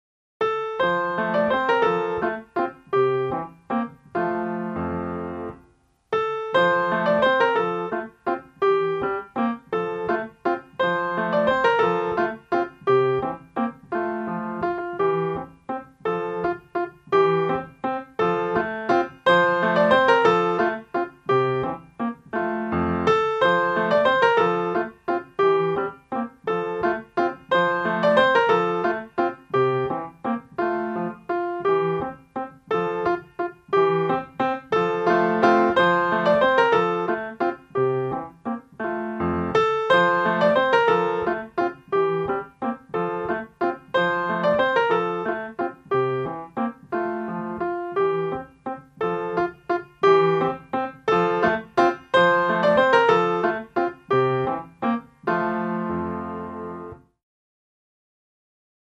MP3 audio (vocal) MP3 audio